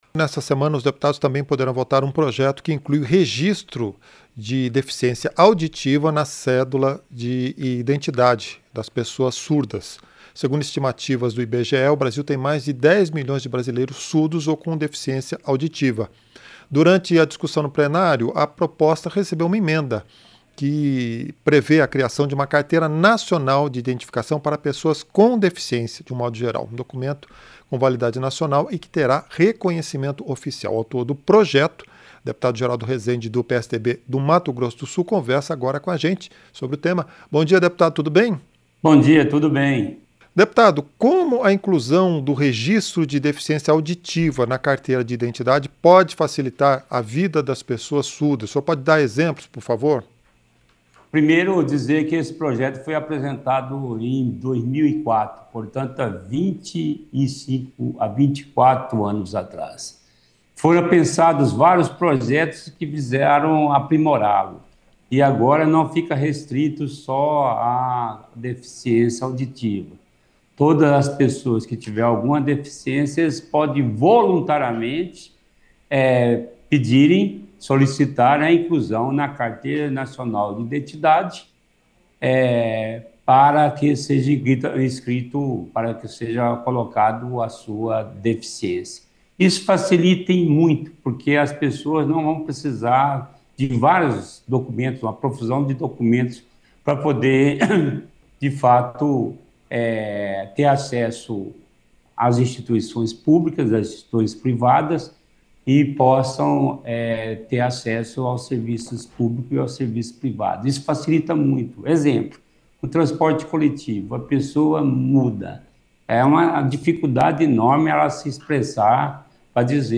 • Entrevista - Dep. Geraldo Resende (PSDB-MS)
Programa ao vivo com reportagens, entrevistas sobre temas relacionados à Câmara dos Deputados, e o que vai ser destaque durante a semana.